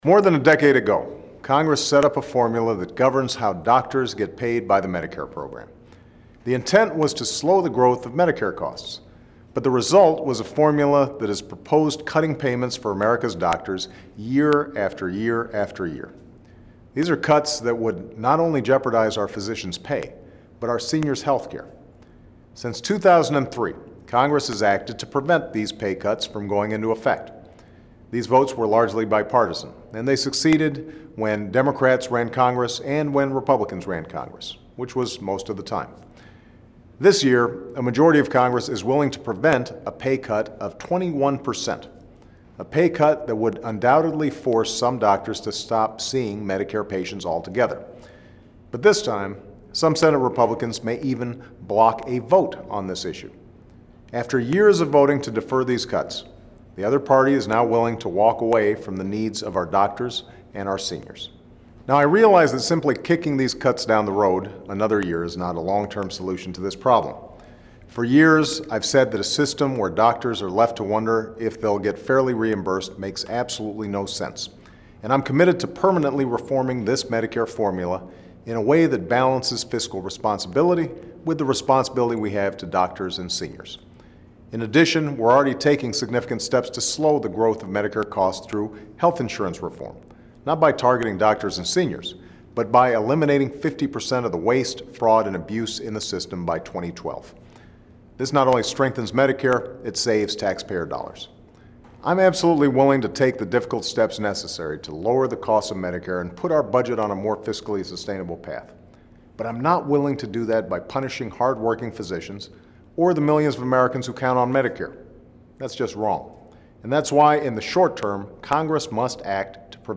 Remarks of President Barack Obama
Weekly Address
Washington, DC